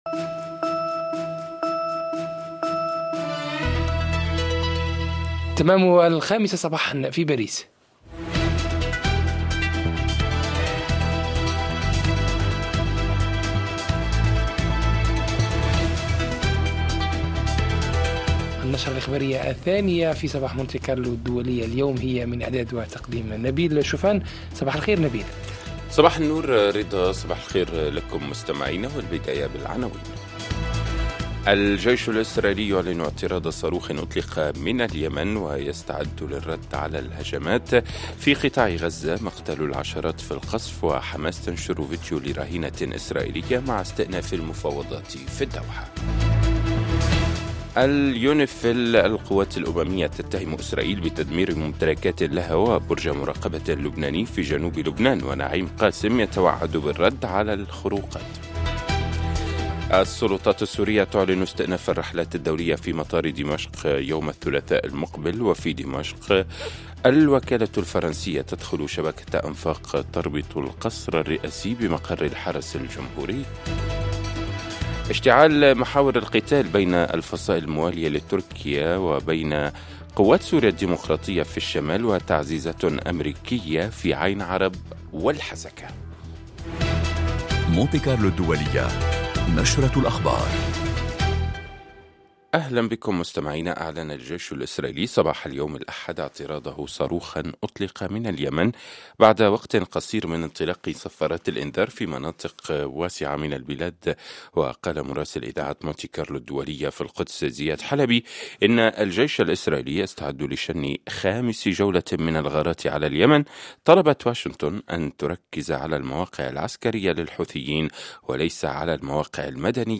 أخبار